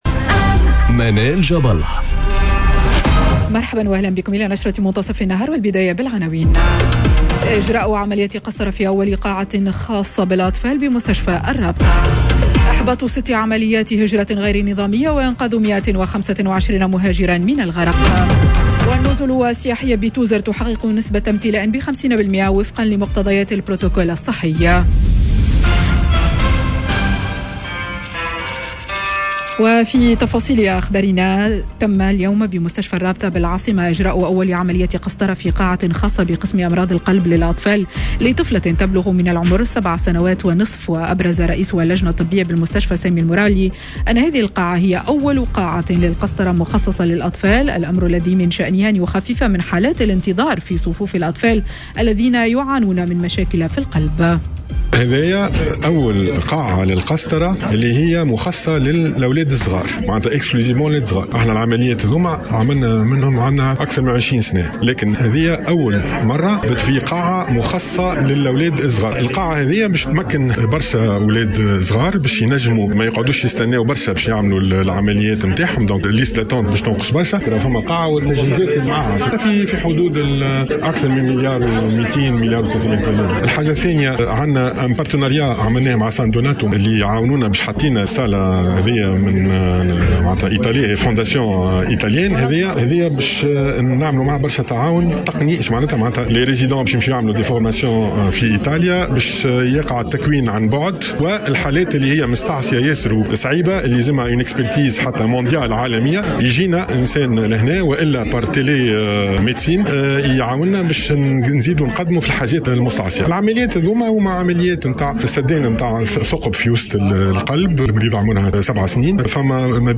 نشرة أخبار منتصف النهار ليوم الإثنين 01 نوفمبر 2021